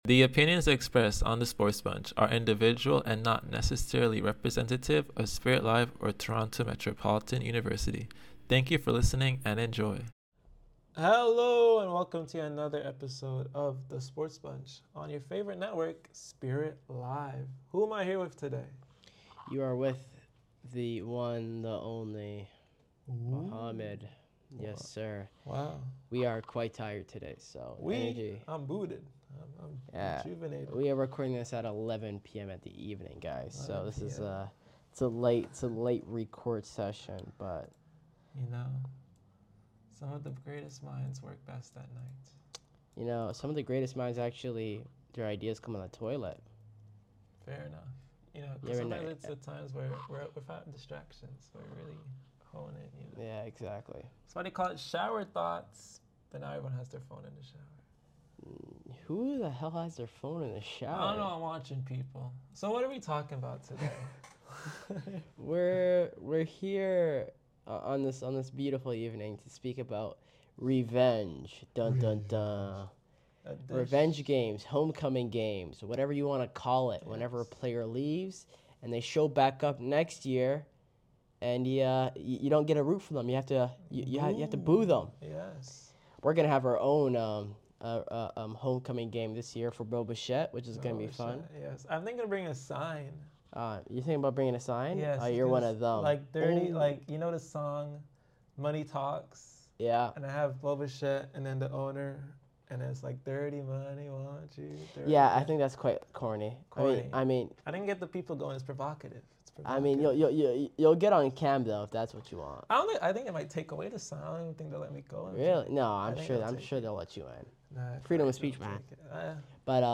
SportsBunch is a student-led talk show where bold opinions, sharp analysis, and real conversations bring the world of sports to life.